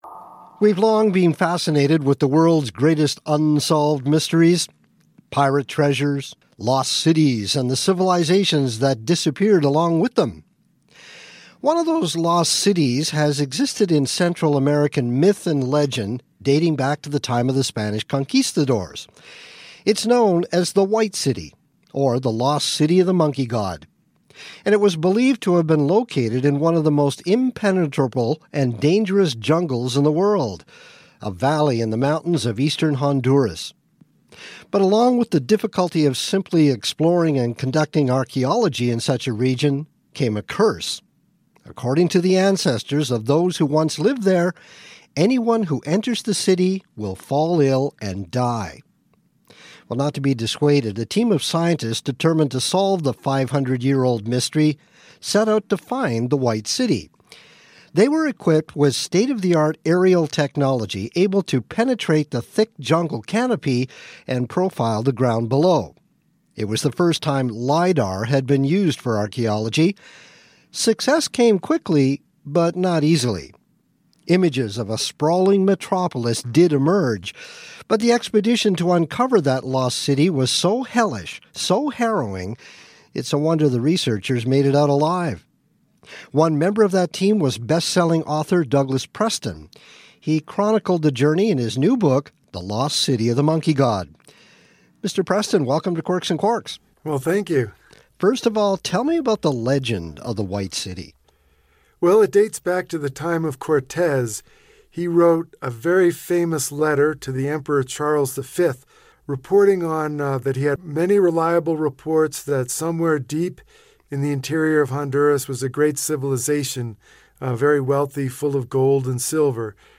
Accent: Canadian, American
TOEFL: Author Douglas Preston describes the discovery of an ancient lost city in Honduras — the Lost City of the Monkey God.